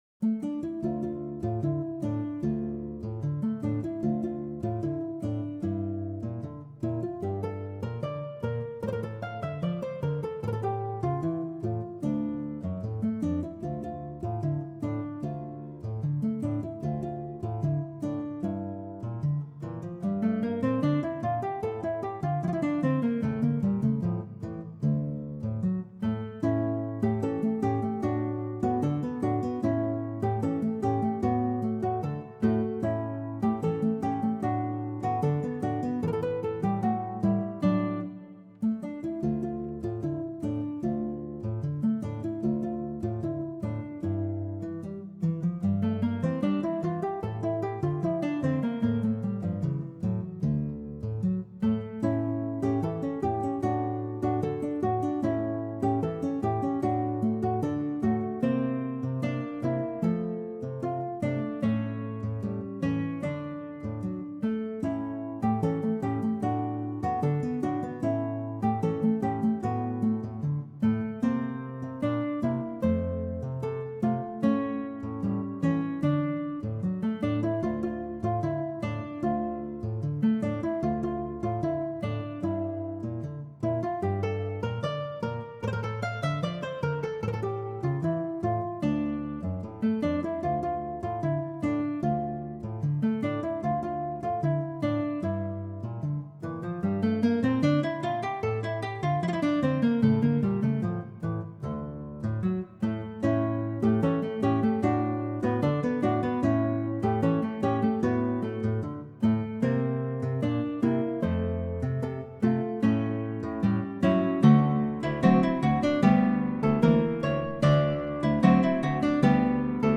⇒    Ecouter la version interprétée par le trio sérénade (minutage 8:02)
Linvitation-au-chateau-tango-acte-3.wav